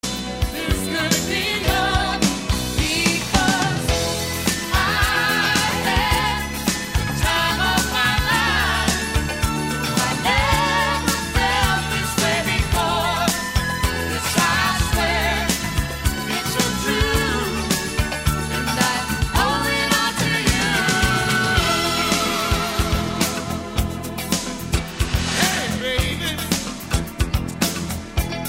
Besetzung: Blasorchester
Tonart: As-Dur
Stil: Medium-Beat